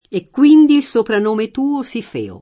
soprannome [ S oprann 1 me ]